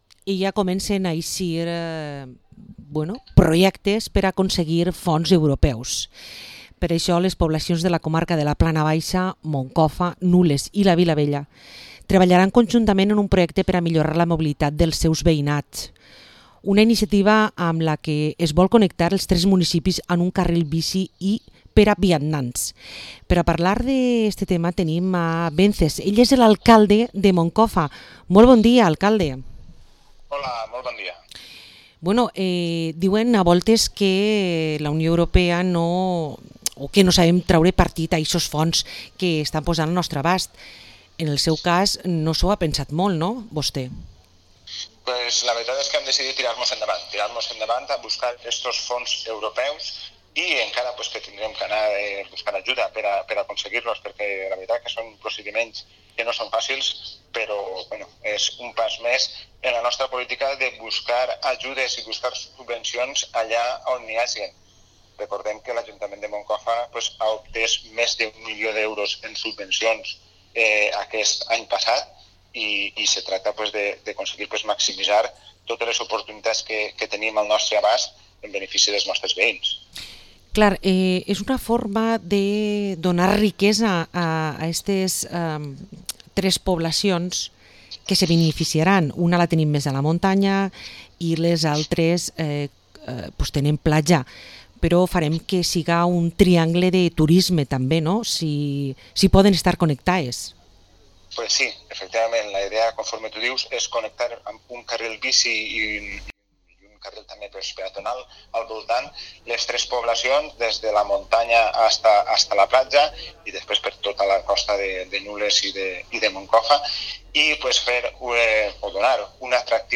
Entrevista al alcalde de Moncofa, Wences Alós